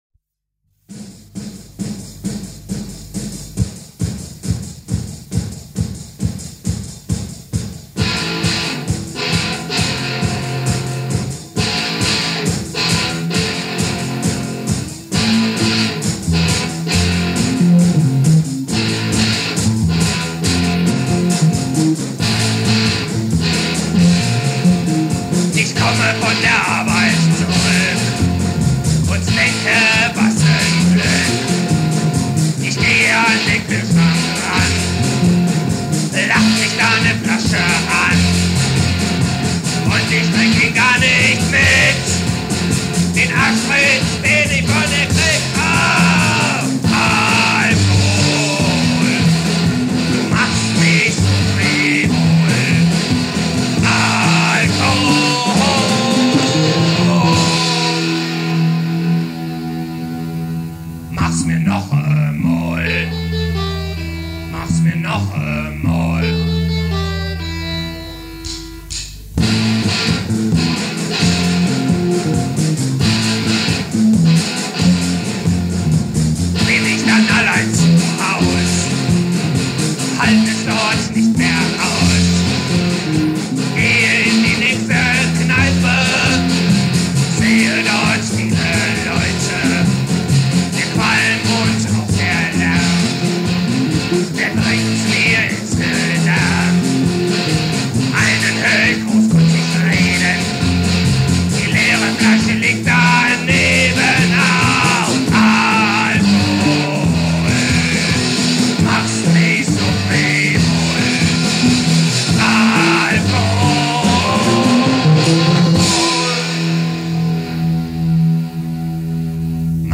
aber erst 2001 gingen sie ins Tonstudio
Party-Zugabe